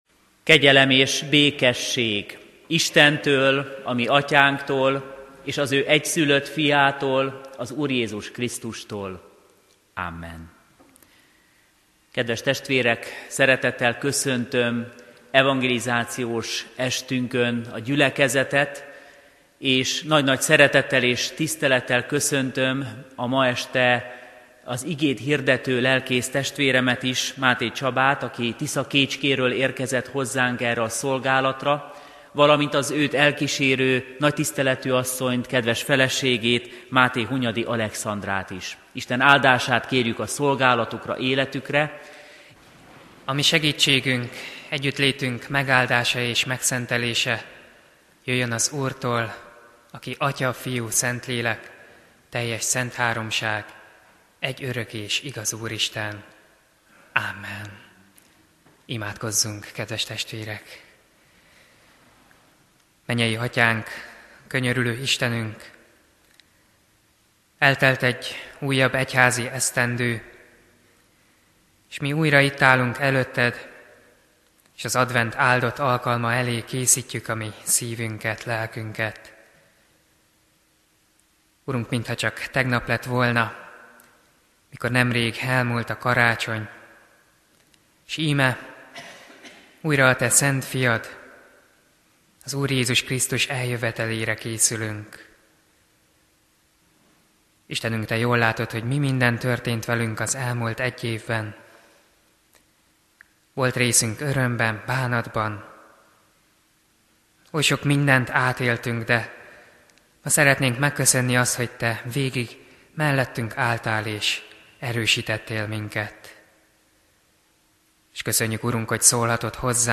Igehirdetések Ugyanaz a szeretet